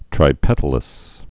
(trī-pĕtl-əs)